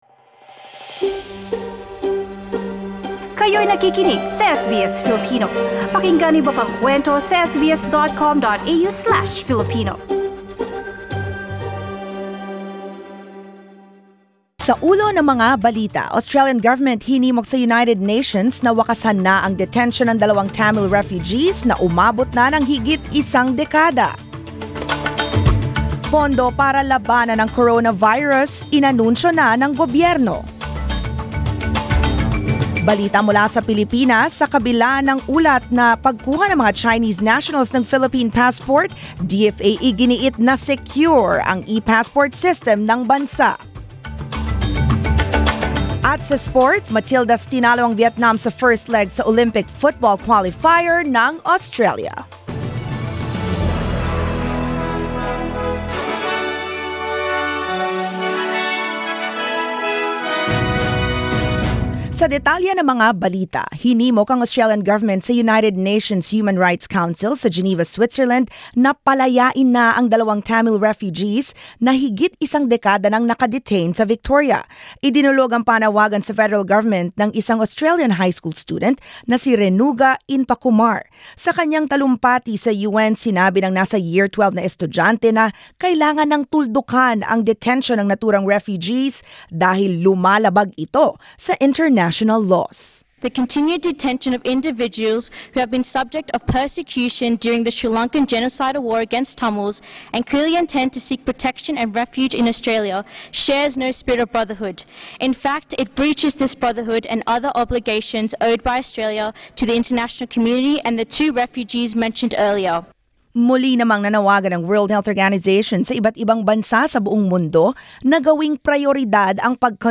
SBS News in Filipino, Saturday 7 March